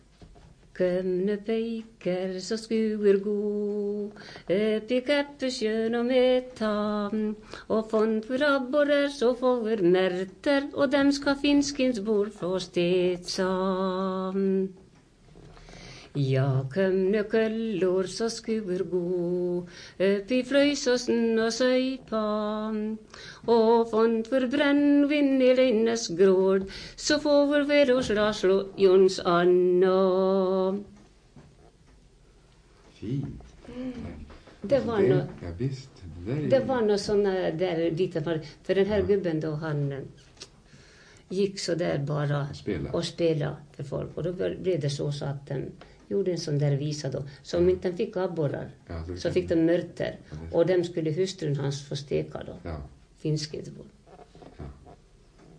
Vispolska, fiolmelodi och hornlåt